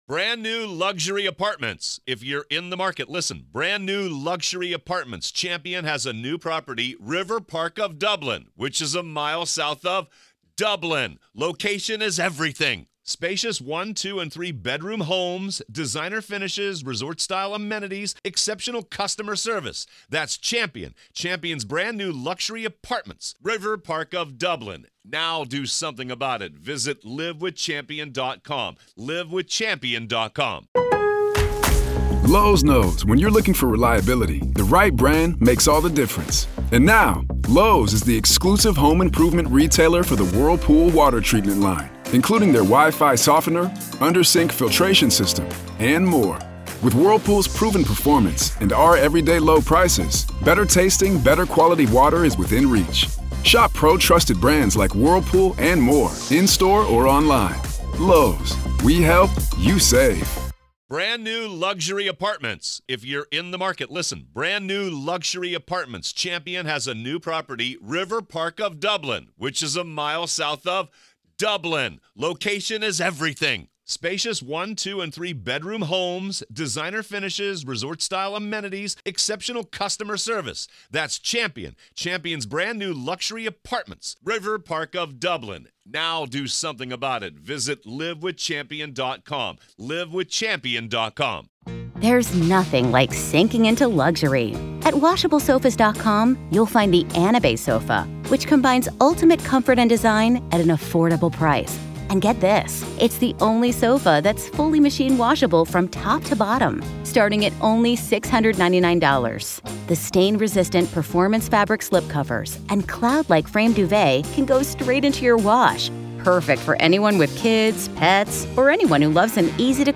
Welcome to the "Week in Review," where we delve into the true stories behind this week's headlines.